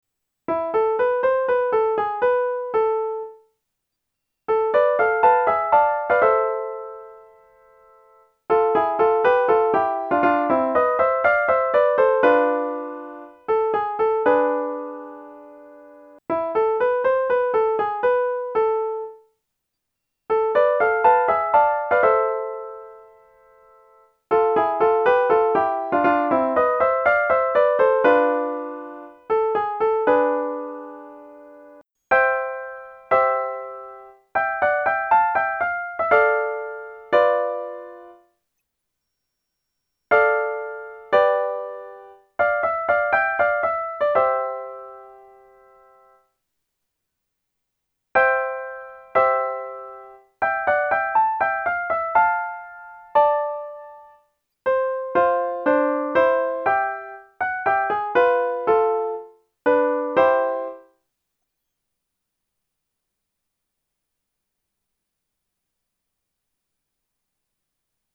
А я взял и сделал в До мажоре.